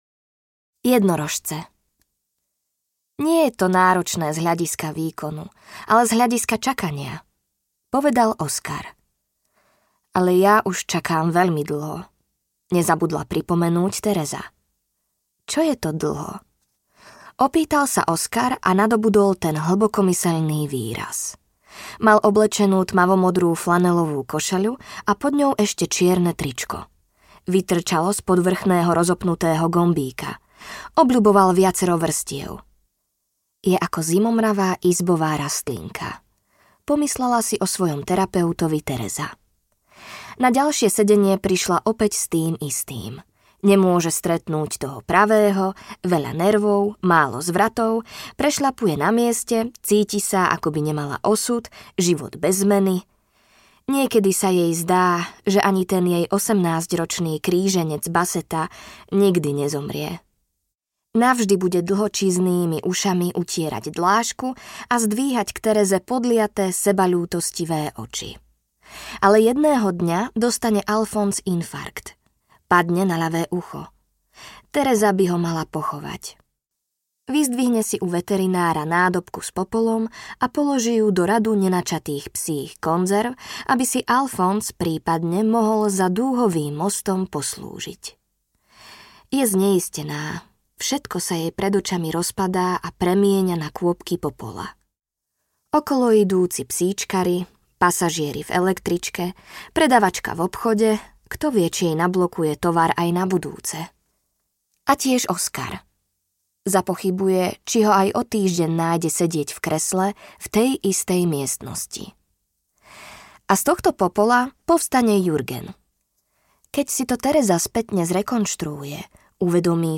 Jednorožce audiokniha
Ukázka z knihy
jednorozce-audiokniha